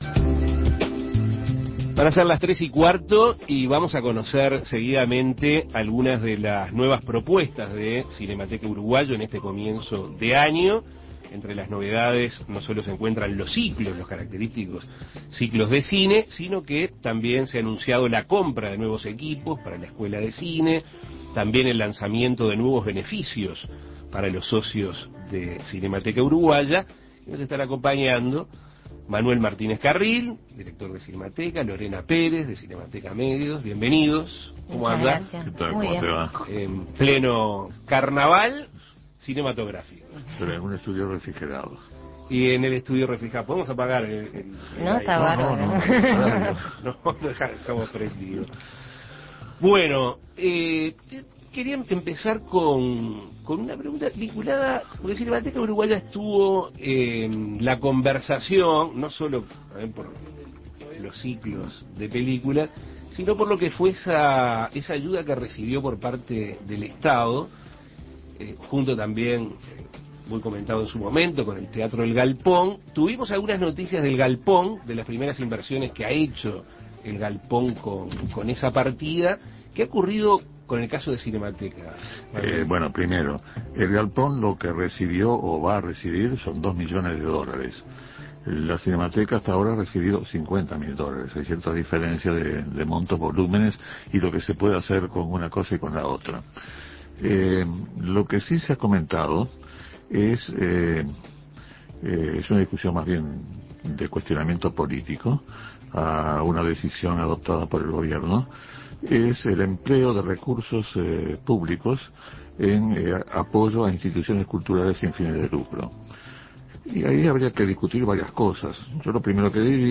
Entrevistas Novedades de Cinemateca Uruguaya Imprimir A- A A+ Cinemateca Uruguaya inicia 2008 con sus característicos ciclos de cine, pero también anuncia la compra de nuevos equipos para la Escuela de Cine, beneficios para sus socios y la idea de llevar su propuesta a otras partes del país con una Distribuidora Alternativa.